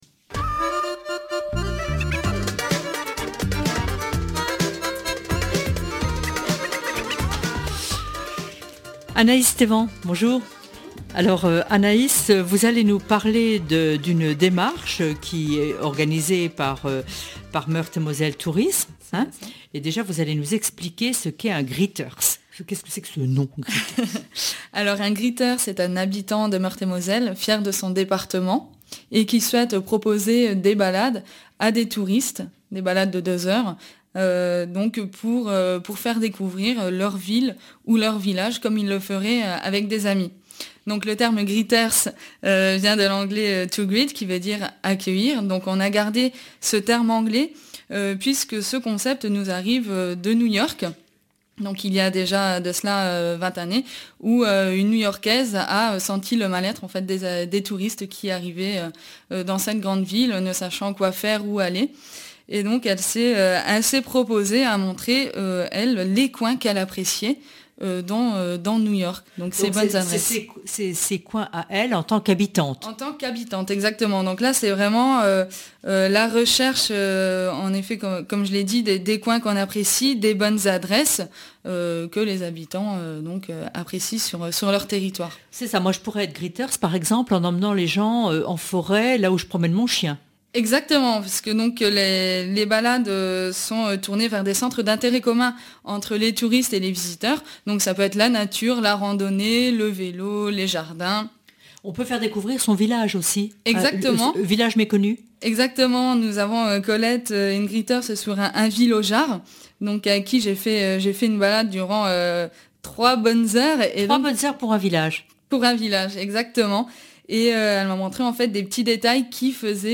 Émission "Bonjour Chez Vous" sur radio Jerico